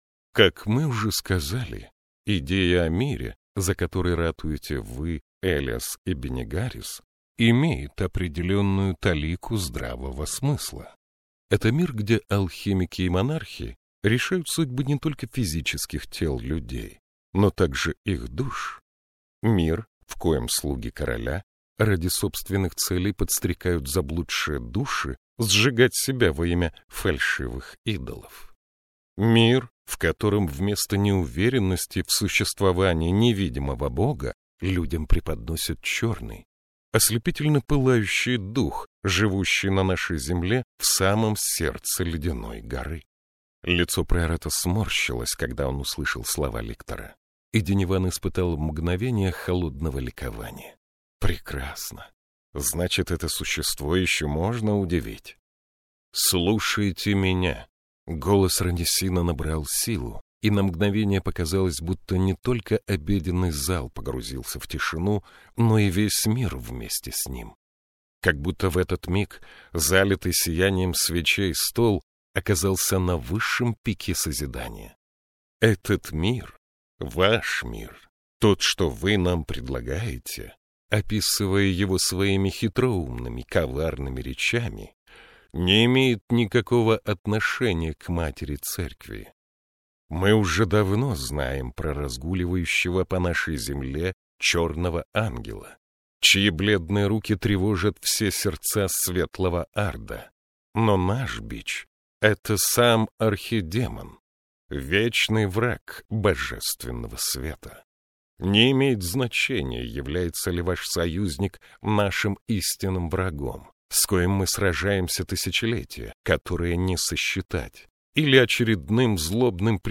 Аудиокнига Скала Прощания. Том 2 | Библиотека аудиокниг